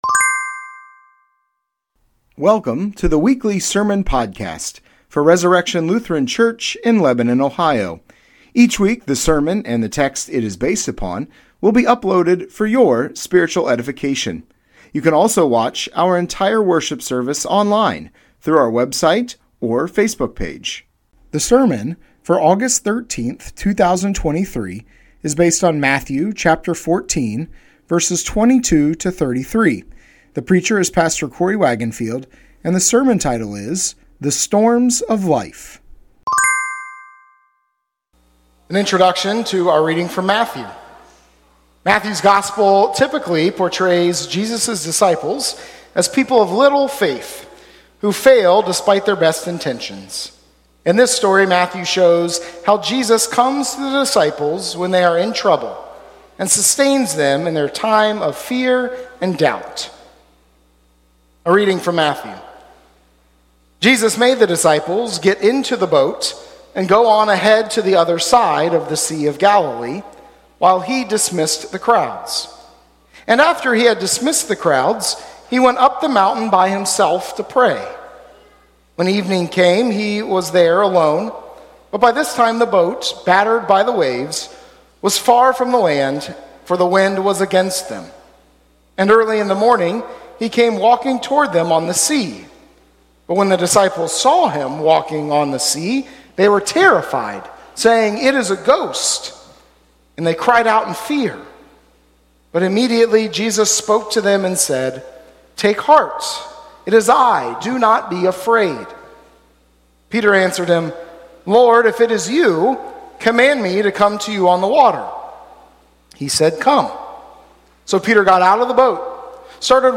Sermon Podcast Resurrection Lutheran Church - Lebanon, Ohio August 13, 2023 - "The Storms of Life"